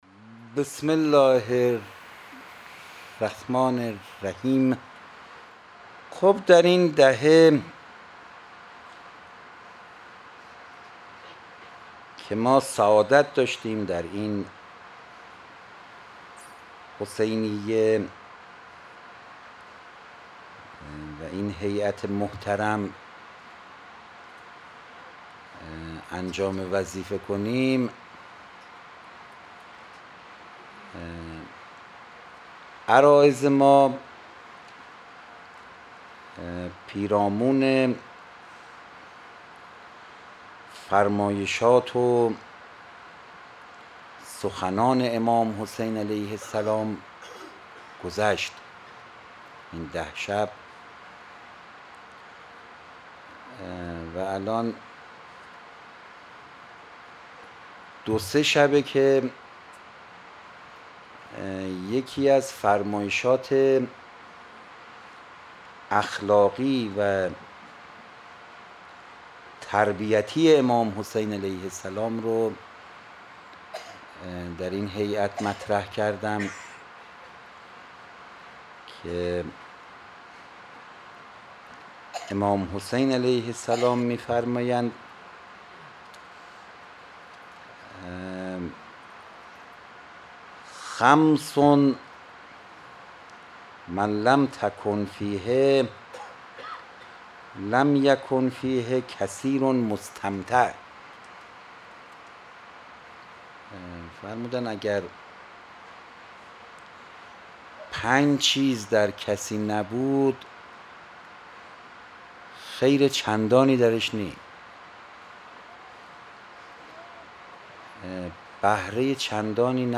sh 11 Sokhanrani
شب دوم محرم